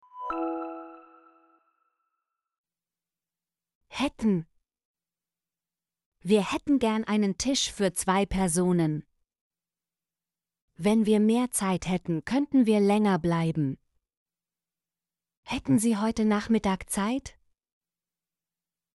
hätten - Example Sentences & Pronunciation, German Frequency List